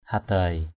/ha-d̪aɪ/